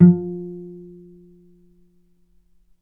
Strings / cello / pizz
vc_pz-F3-mf.AIF